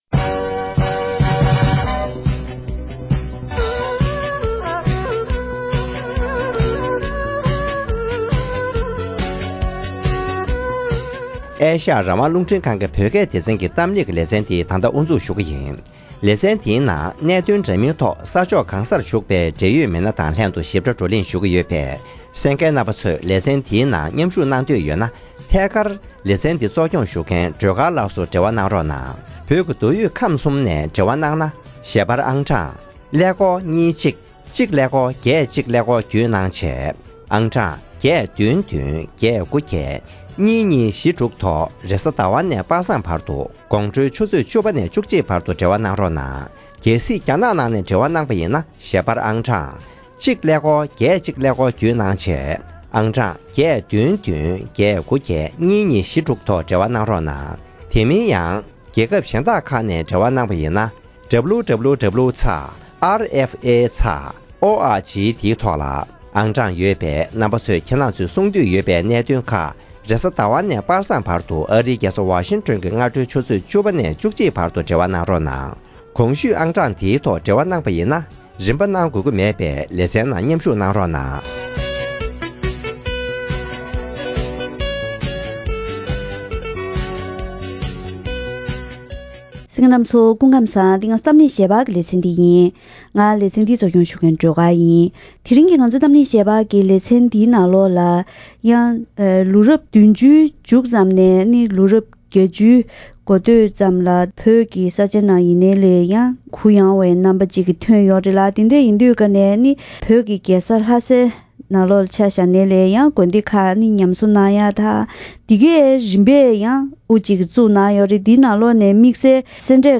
ལོ་རབས་བརྒྱད་བཅུའི་ནང་དམངས་ཁྲོད་ནས་སེ་འབྲས་དགའ་གསུམ་ཉམས་གསོ་ཇི་ལྟར་གནང་ཡོད་པའི་སྐོར་མི་སྣ་ཁག་ཅིག་དང་ལྷན་དུ་གླེང་མོལ་ཞུས་པ།